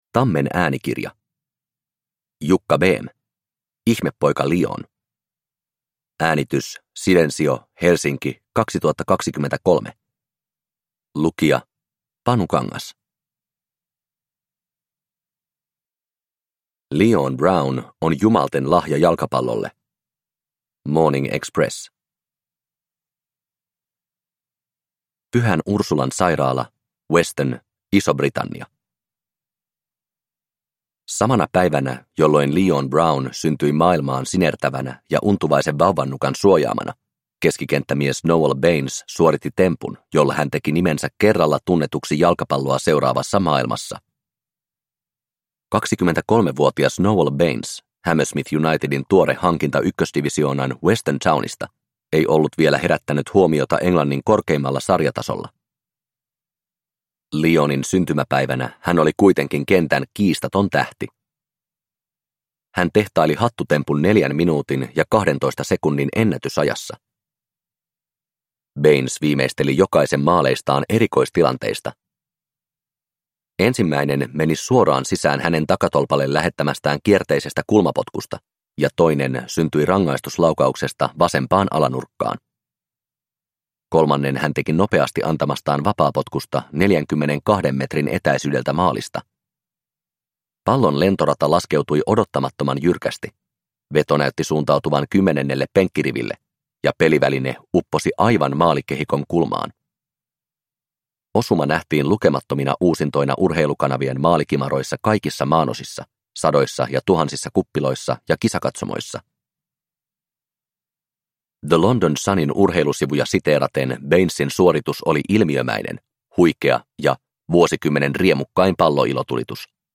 Ihmepoika Leon – Ljudbok – Laddas ner